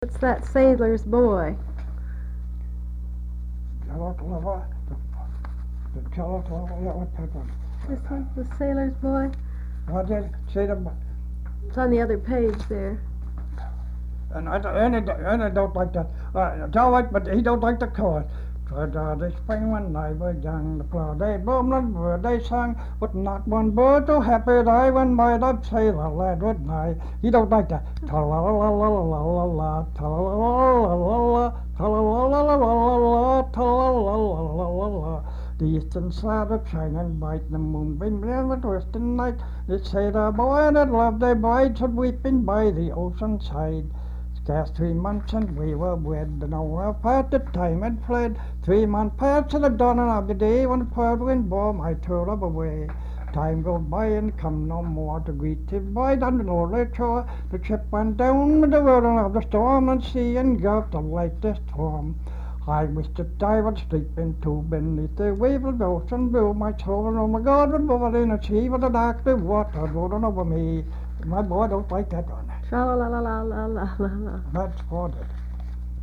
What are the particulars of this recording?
sound tape reel (analog)